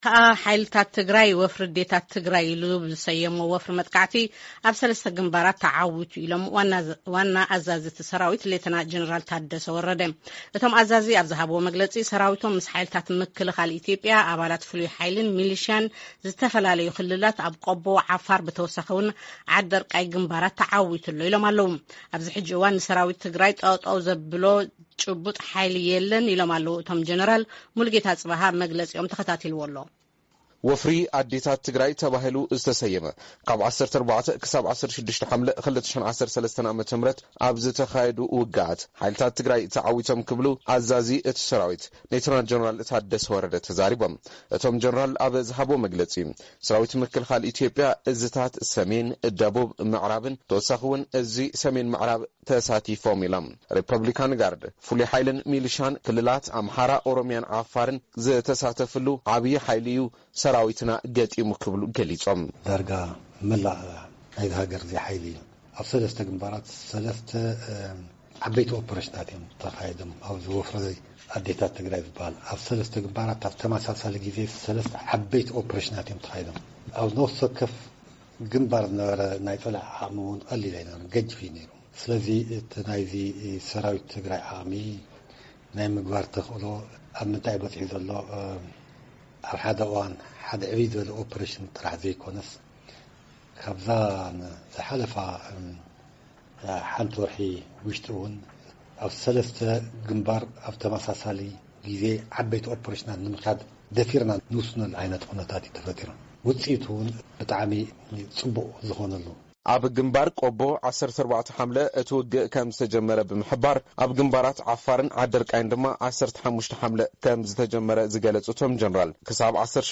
መግለጺ ኣዛዚ ሓይልታት ትግራይ ሌተናል ጀነራል ታደሰ